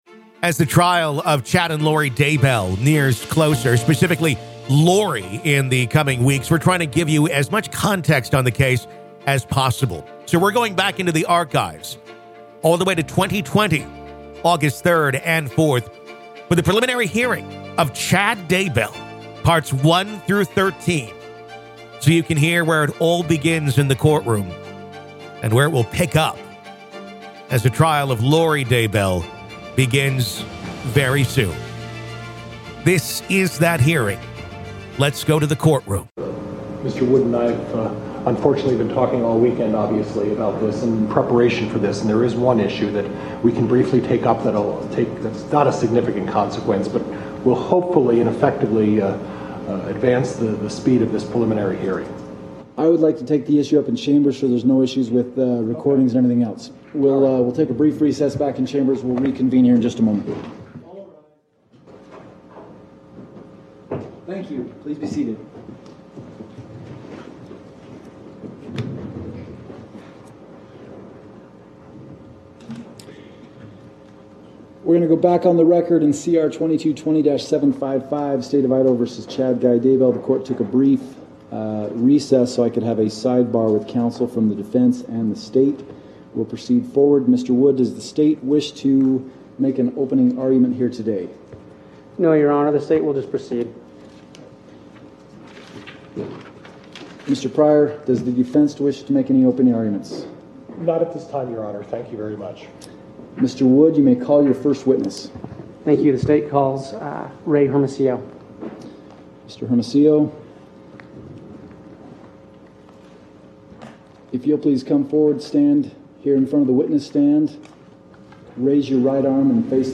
Listen To The Full Preliminary Hearing Of Chad Daybell, Part 1
This is the complete preliminary hearing of Chad Daybell, originally recorded August 3rd and 4th of 2020.